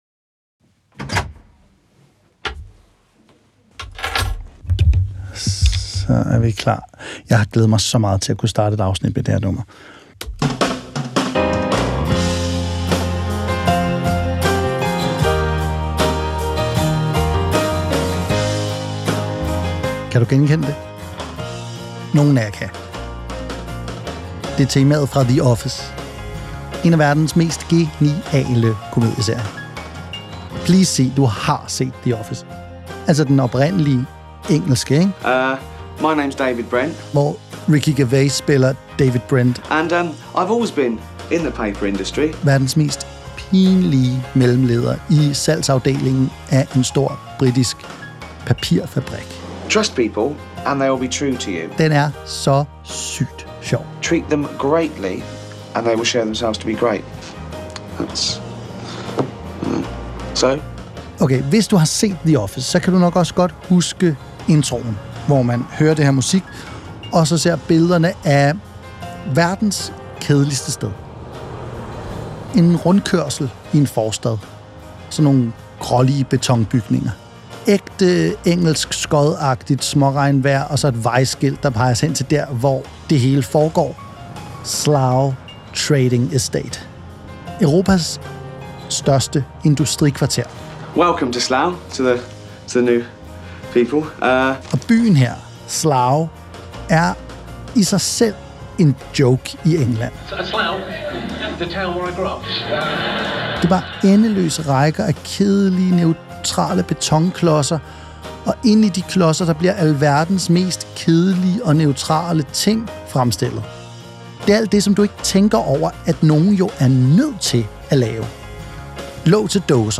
Det er actionfyldte lydfortællinger baseret på den vilde virkelighed.